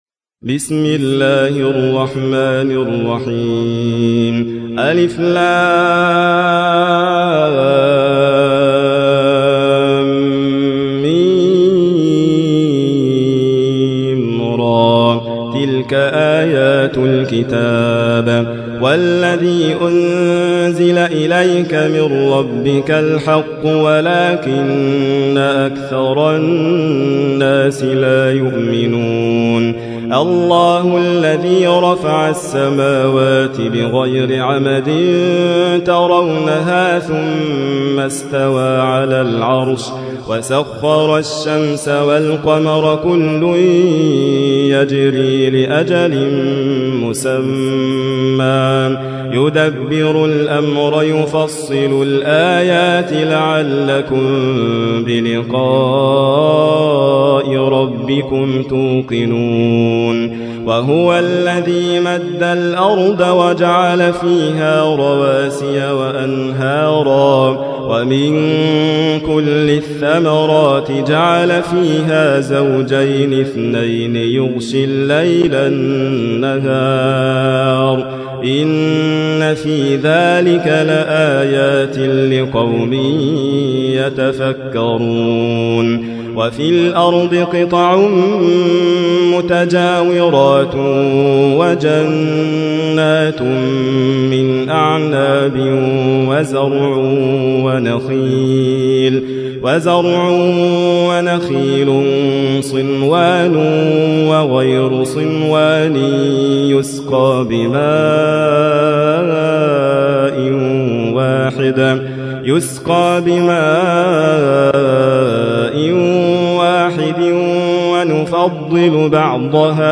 تحميل : 13. سورة الرعد / القارئ حاتم فريد الواعر / القرآن الكريم / موقع يا حسين